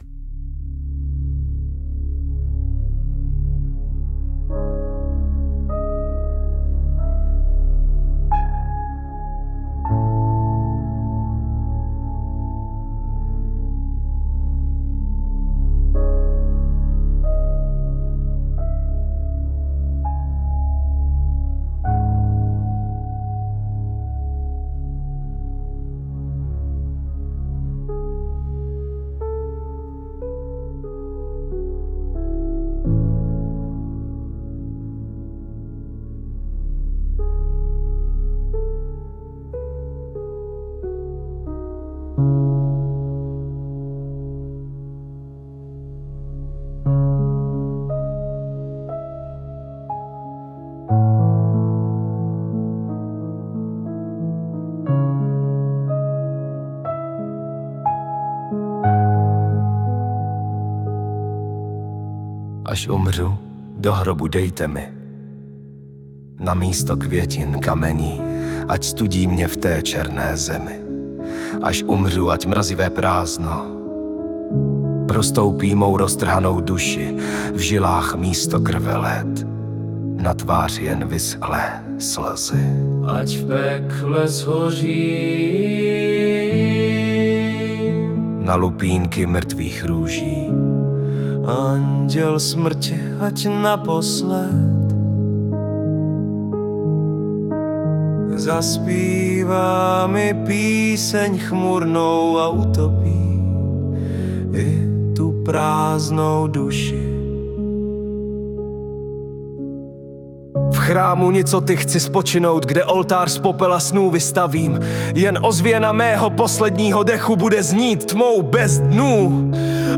Silná báseň, mrazivá, hudba ji dodává tu smutnou, temnou hloubku...